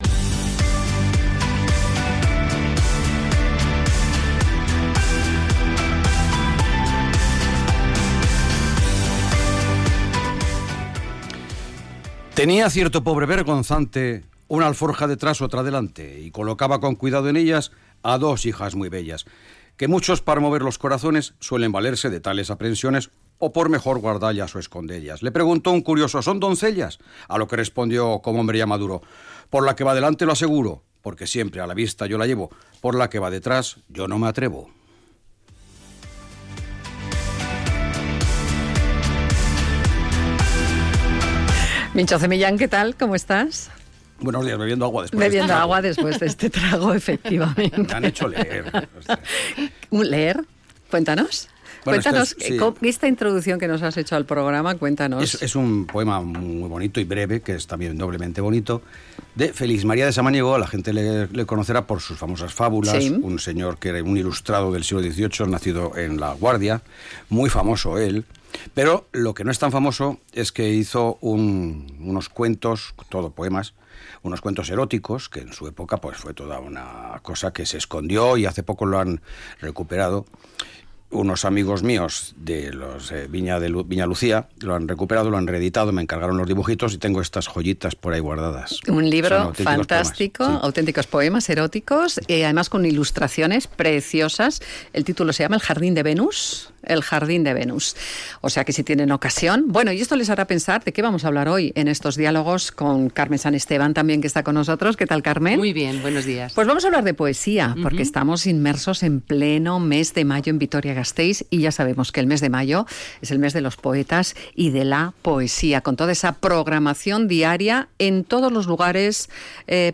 La belleza de los números ha inspirado a los poetas y éstos se han servido de las matemáticas para crear sus versos. Entrevistamos
se lanzan a recitar algunos versos, en unos Diálogos de lo más poéticos